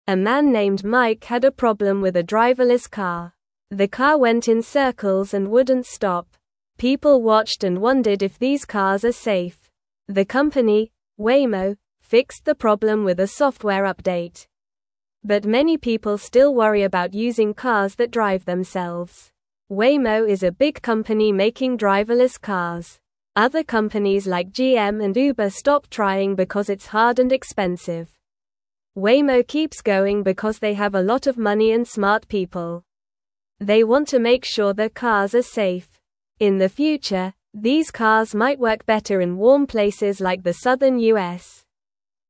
Normal
English-Newsroom-Beginner-NORMAL-Reading-Driverless-Cars-Safe-or-Not-for-Everyone.mp3